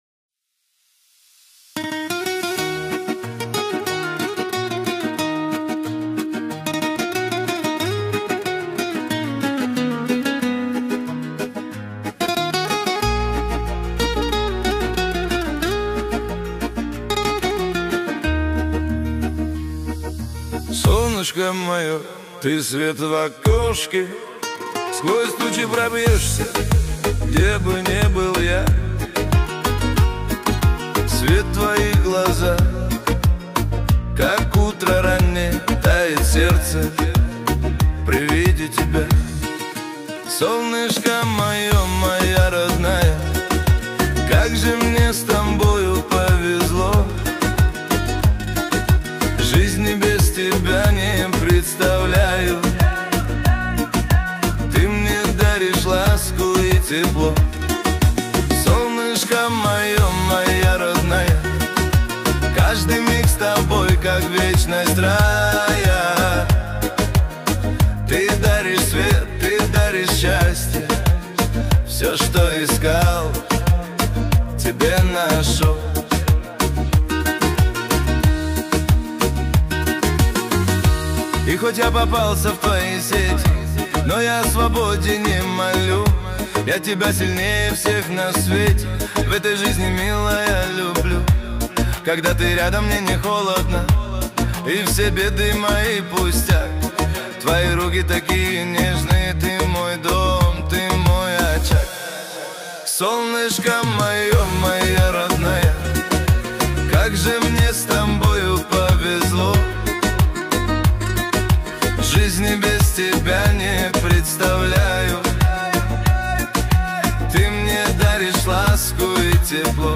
15 декабрь 2025 Русская AI музыка 104 прослушиваний